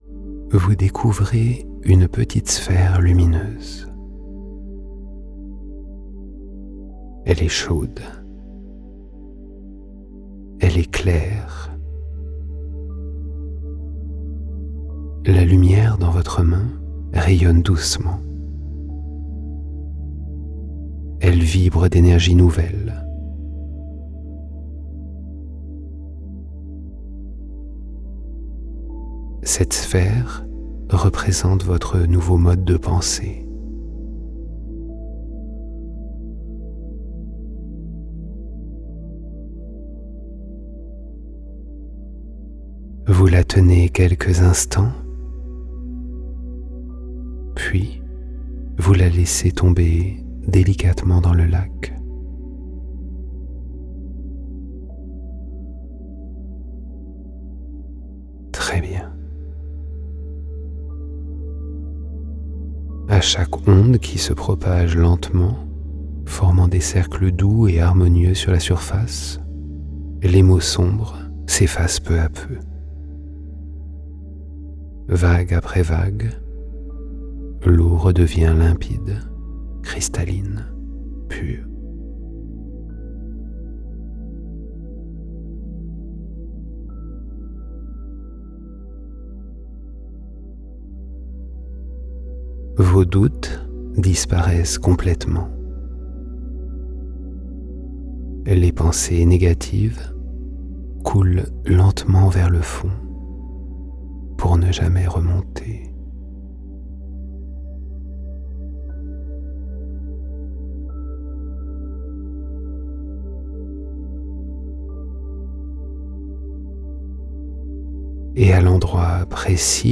Hypnose du sommeil pour une Régénération Profonde et une Confiance Renouvelée
Avec une voix calme et des suggestions hypnotiques fluides, tu relâches les pensées qui t'alourdissent et tu ouvres ton cœur à une confiance stable, sereine, lumineuse.